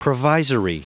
Prononciation du mot provisory en anglais (fichier audio)